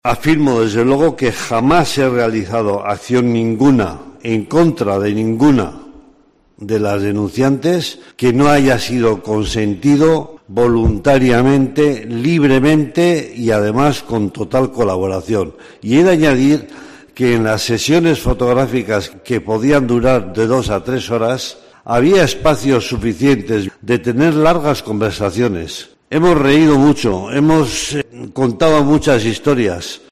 en el último turno de palabra durante el juicio en su contra en la Audiencia de Gipuzkoa